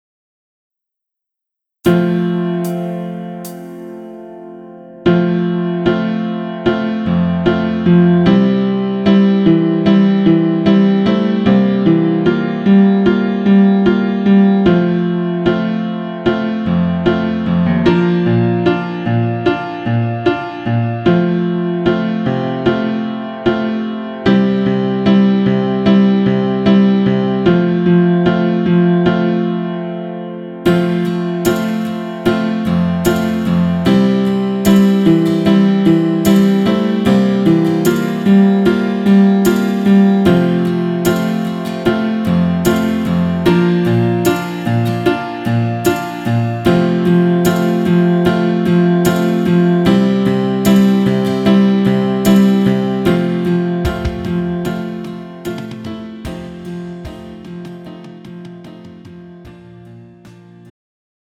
음정 원키
장르 pop 구분 Pro MR